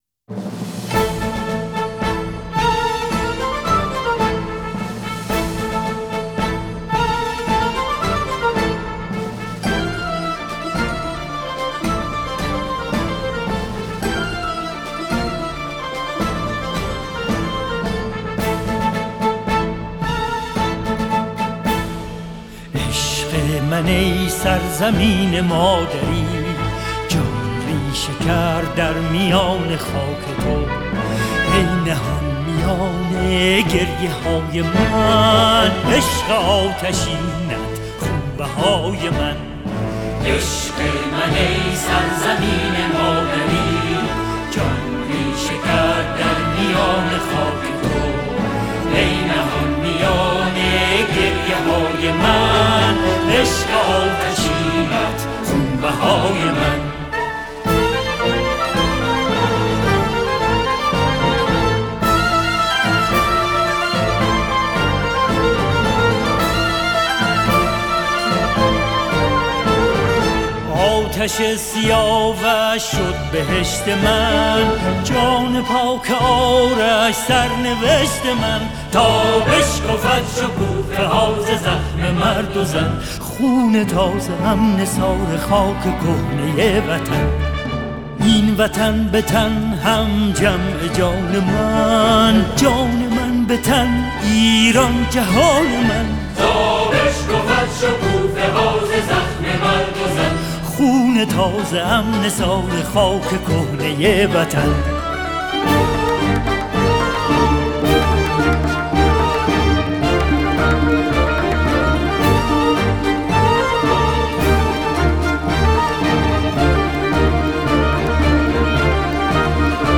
اركستر و آواز